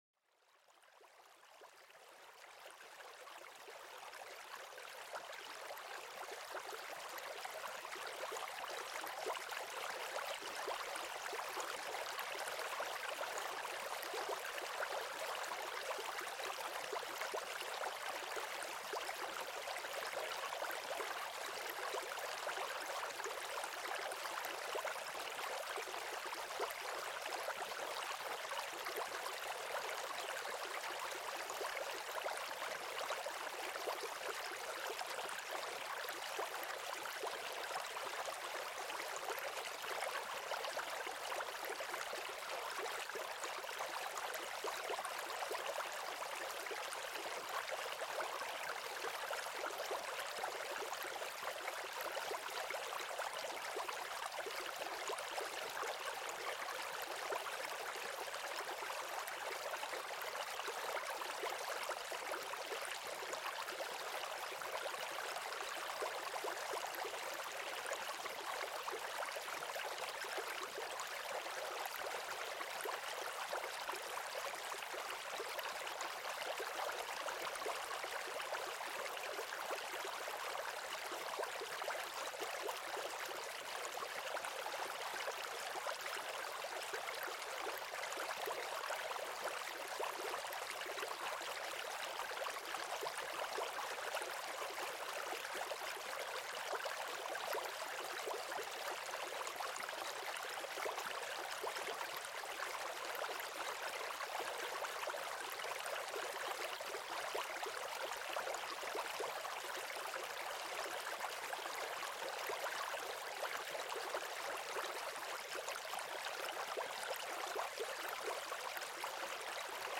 Rivière Douce : Relaxation et Sommeil Profond grâce aux Sons Apaisants de la Nature